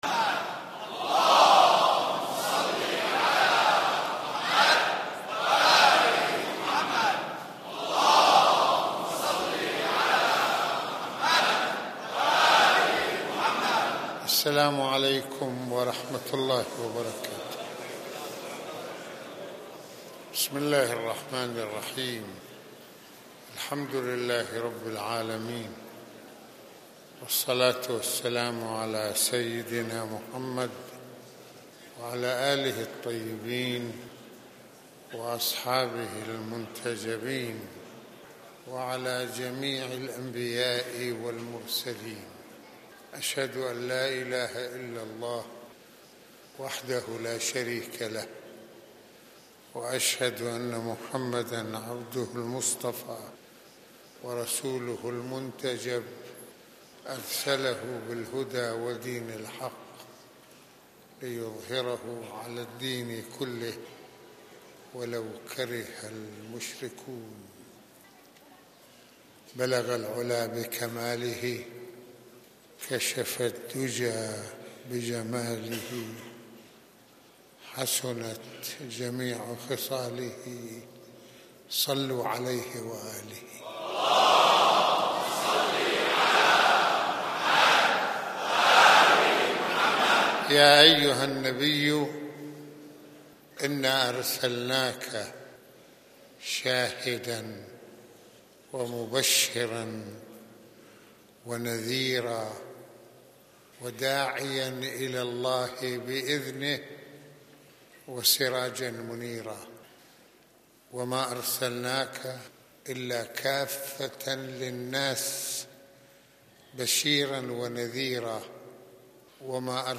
خطبة الجمعة المكان : مسجد الإمامين الحسنين (ع)